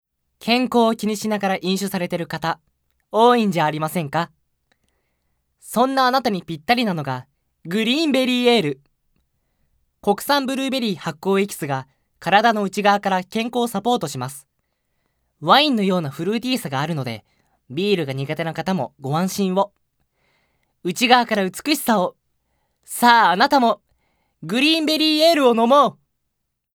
高音出ます。
ボイスサンプル、その他
ナレーション２